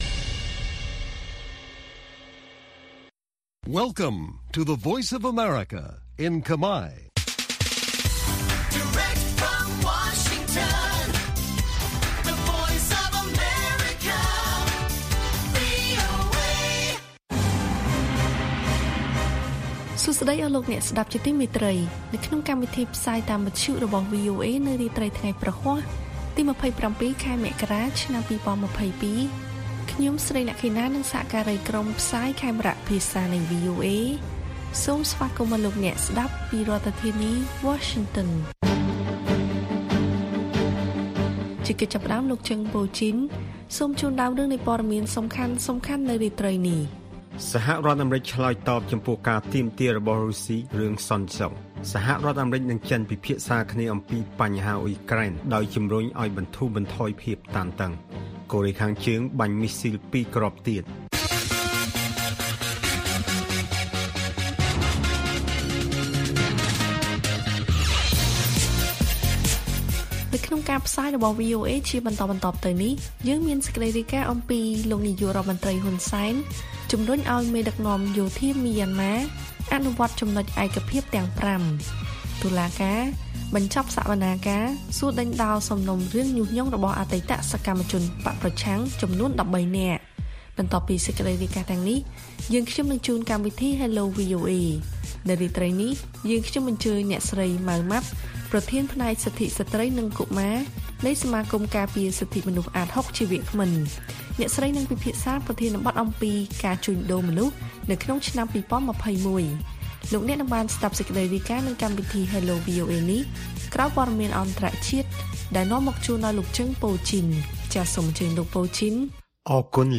ព័ត៌មានពេលរាត្រី៖ ២៧ មករា ២០២២